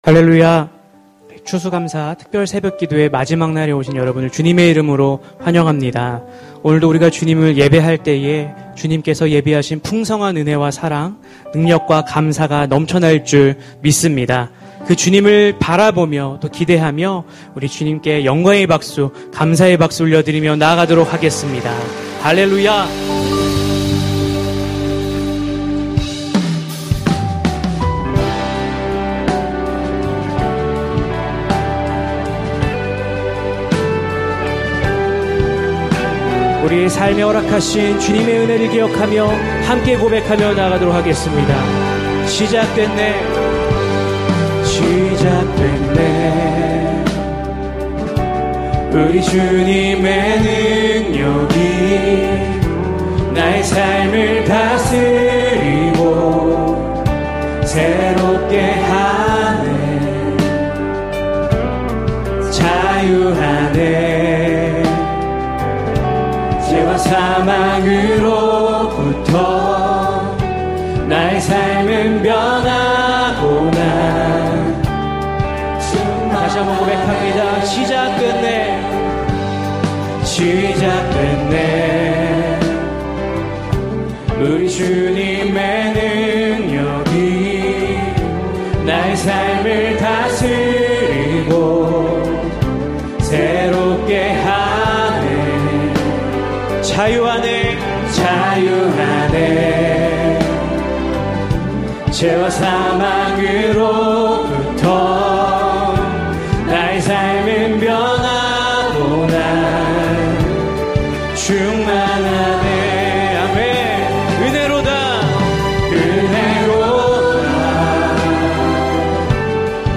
절기예배